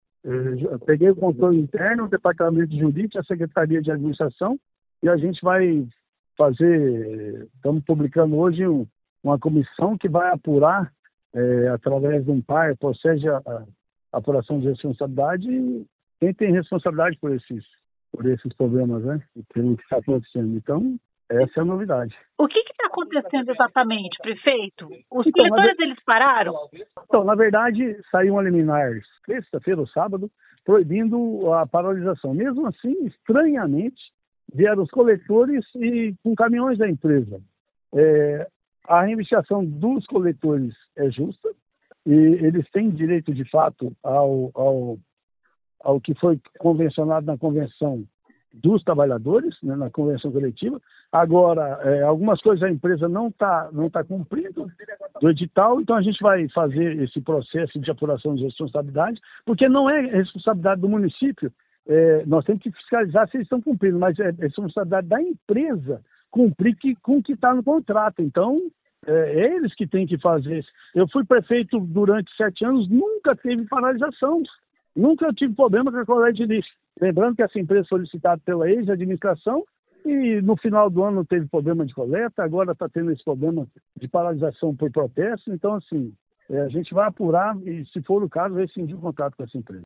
Ouça o que diz o prefeito: